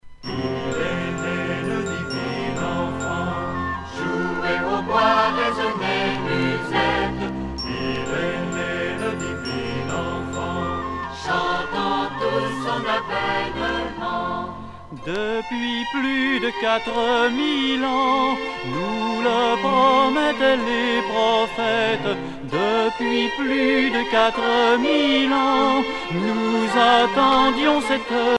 Noël
Catégorie Pièce musicale éditée